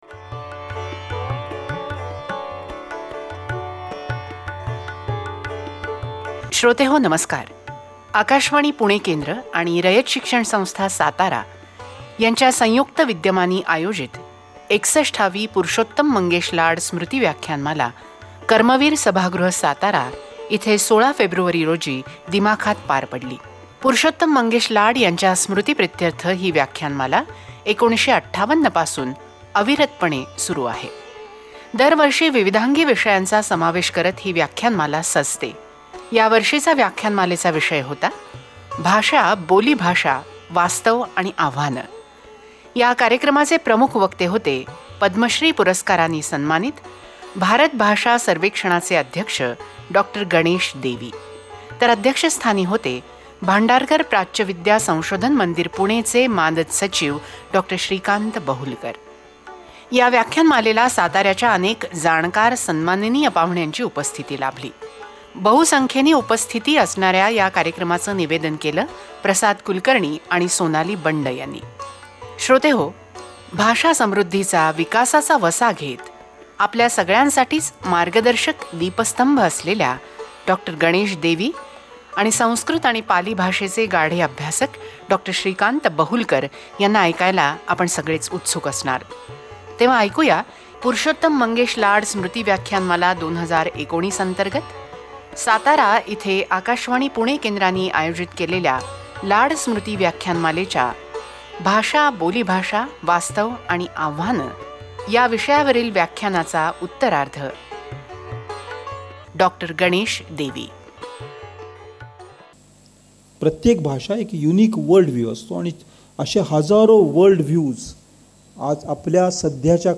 LECTURES
"LAD SMRUTIVYAKHYANMALA" Lecturer in Satara, maharashtra